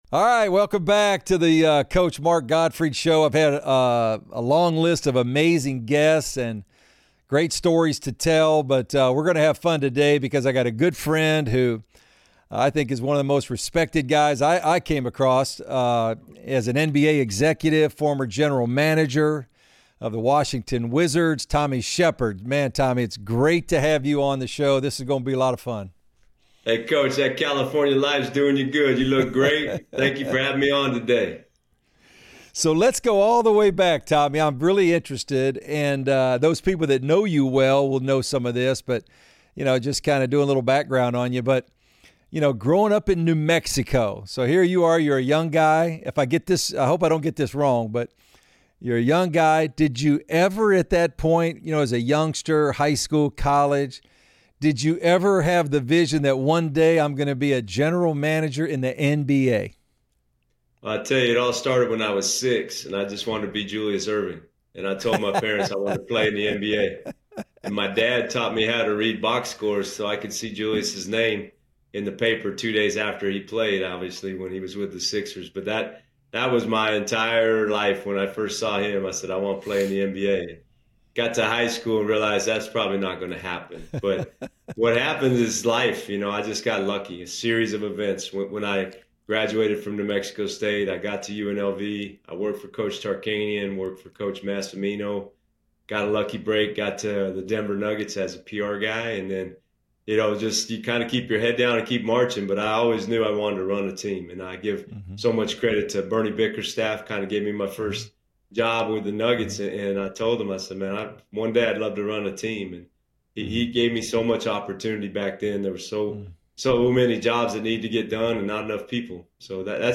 Coach Mark Gottfried brings you conversations of successful people and the decisions they made along the way of their lives, to inspire, encourage and motivate us in our own lives.
Conversations